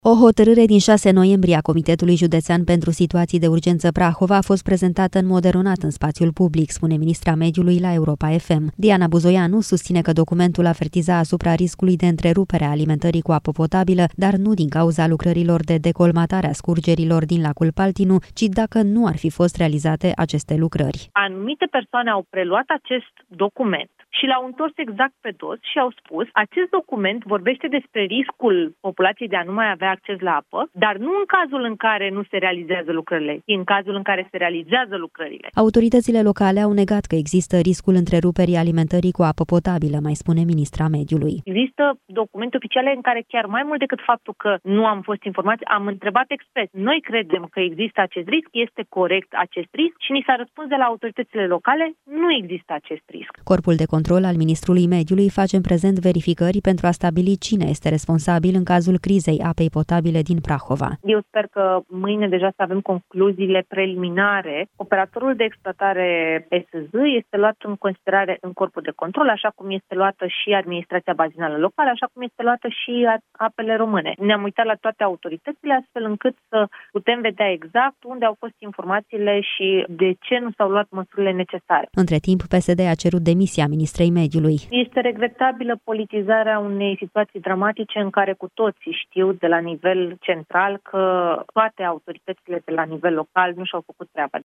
O hotărâre din 6 noiembrie a Comitetului Județean pentru Situații de Urgență Prahova a fost prezentată în mod eronat în spațiul public, spune ministra Mediului la Europa FM.
Ministrul Mediului, Diana Buzoianu: „Anumite persoane au luat acest document și l-au întors exact pe dos”